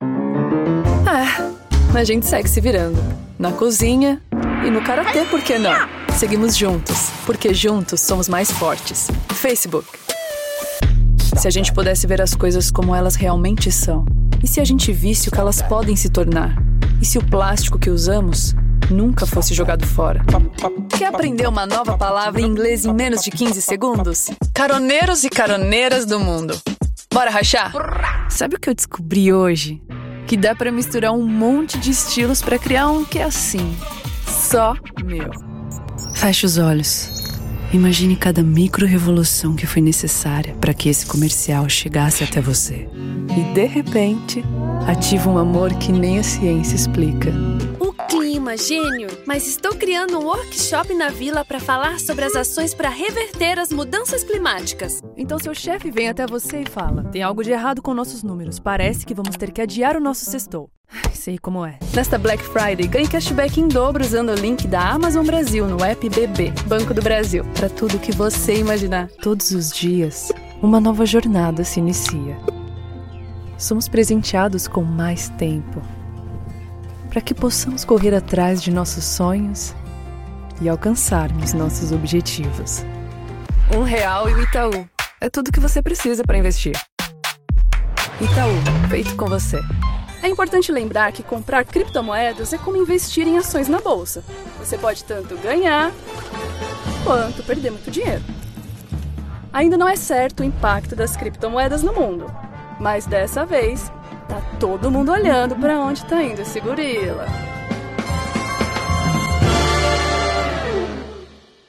Portugais (brésilien)
Motivationnel
Émotionnel